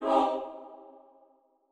SouthSide Chant (51).wav